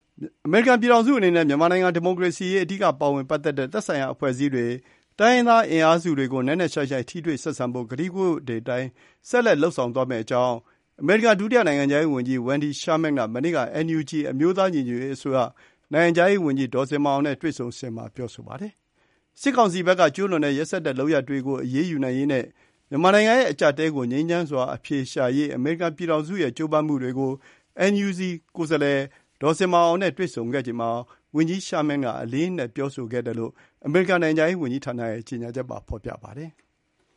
အမေရိကန်ပြည်ထောင်စုအနေနဲ့ မြန်မာနိုင်ငံ ဒီမိုကရေစီရေး အဓိကပါဝင်ပတ်သက်တဲ့ သက်ဆိုင်ရာ အဖွဲ့အစည်းတွေ၊ တိုင်းရင်းသား အင်အားစုတွေကို နက်နက်ရှိုင်းရှိုင်း ထိတွေ့ဆက်ဆံဖို့ ကတိကဝတ်တွေအတိုင်း ဆက်လက် လုပ်ဆောင်သွားမယ့်အကြောင်း အမေရိကန် ဒုတိယနိုင်ငံခြားရေးဝန်ကြီး Wendy Sherman က ပြောဆိုပါတယ်။